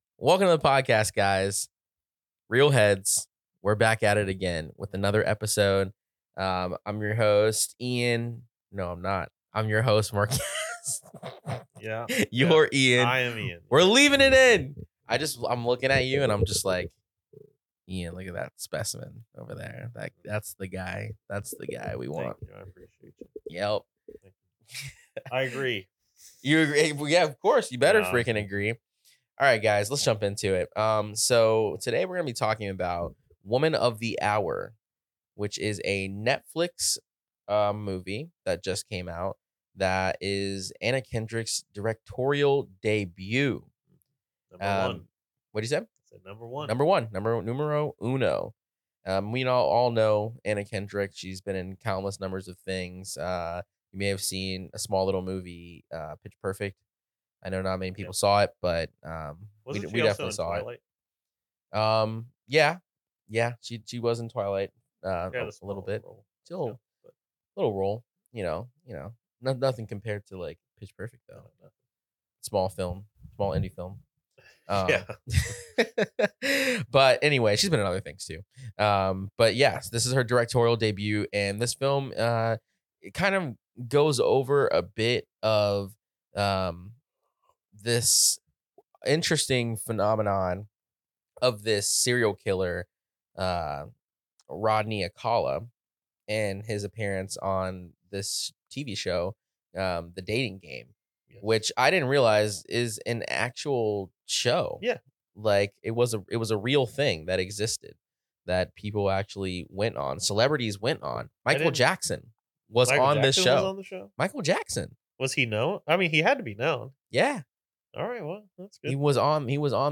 Two friends, in a room, with reel thoughts about films that came from our heads.